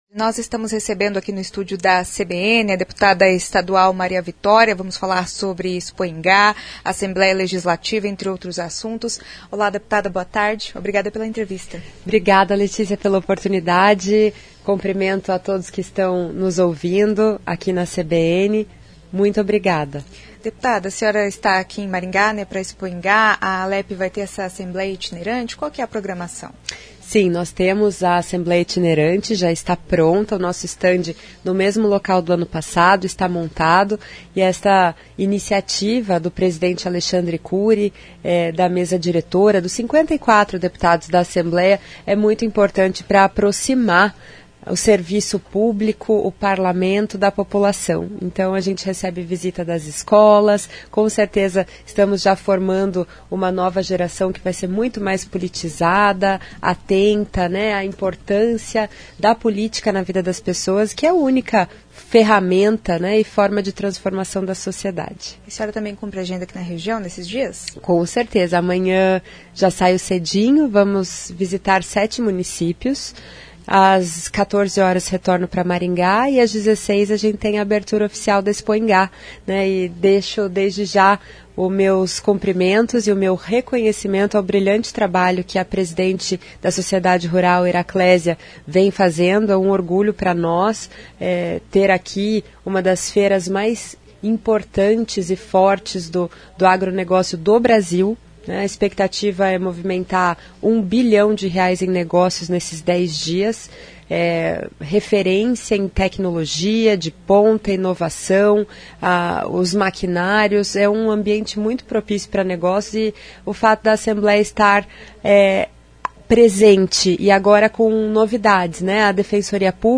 Maria Victória, Deputada estadual . CBN Maringá.